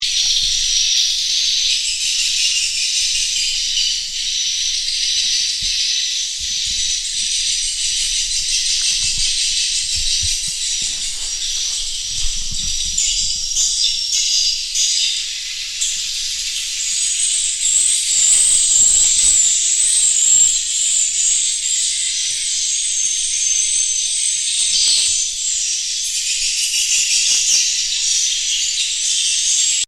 all-day siren call through booming speakers to a small bird whose edible nests "” at almost $1,000 a pound "” produce a broth that is highly prized, and highly priced, in China.
And between the birds and the electronic calls, the chirping never stops.
inside-a-swiftlet-house-electronic.mp3